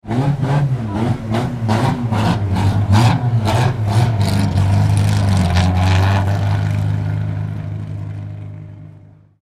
Car-exhaust-sound